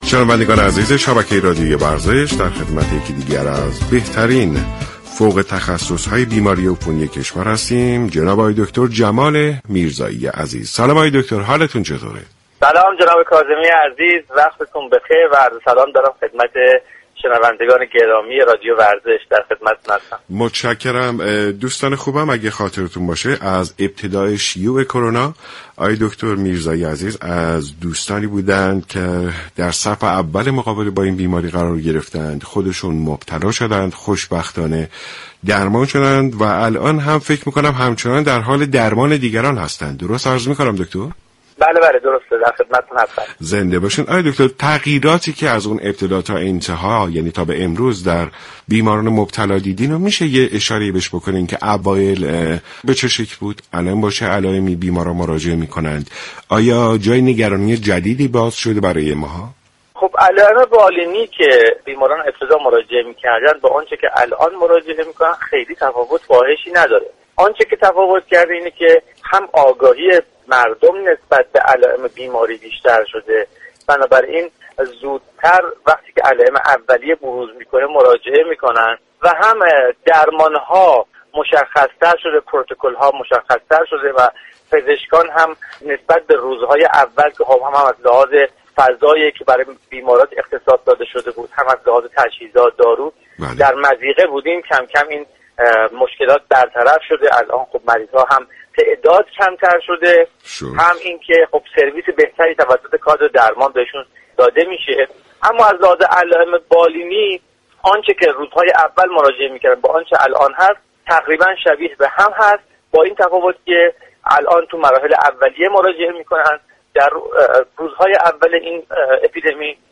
شما می توانید از طریق فایل صوتی پیوست شنونده بخشی از برنامه سلامت باشیم رادیو ورزش كه شامل صحبت های این متخصص درباره كرونا و پاسخگویی به سوالات عموم است؛ باشید.